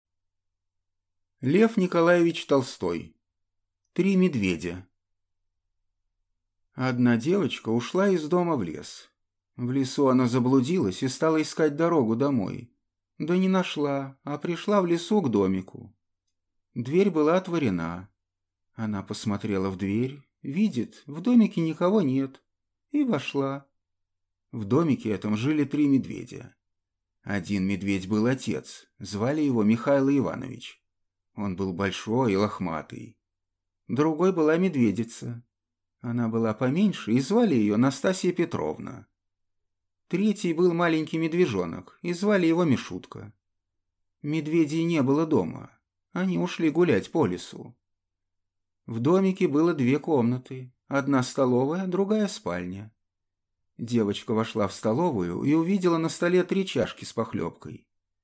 Аудиокнига Три медведя | Библиотека аудиокниг
Прослушать и бесплатно скачать фрагмент аудиокниги